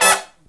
HIGH HIT16-R.wav